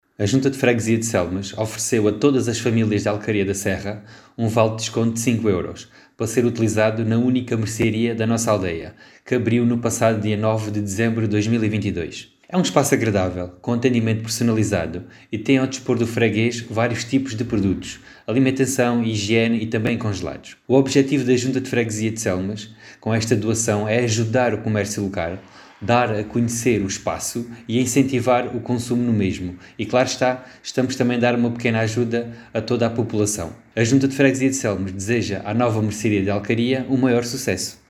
As explicações foram deixadas pelo presidente da junta de freguesia de Selmes, Sérgio Borges.